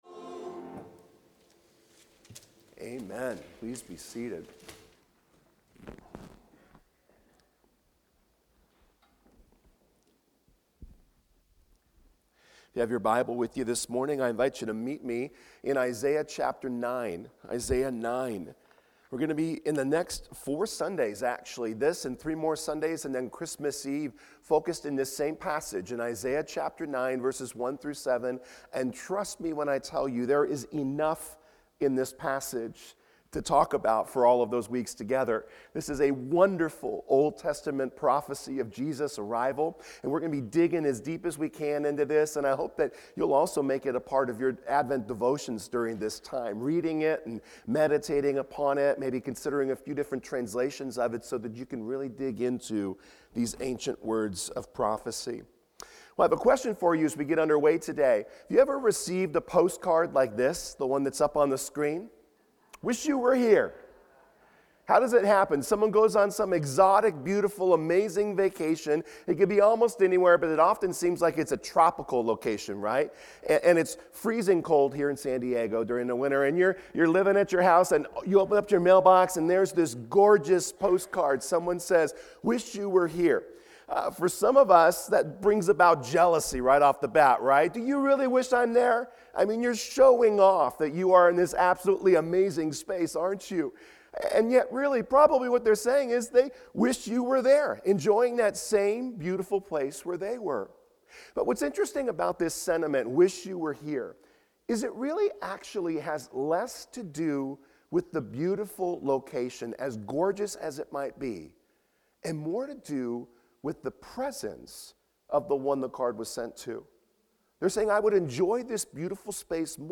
Promised Savior | Fletcher Hills Presbyterian Church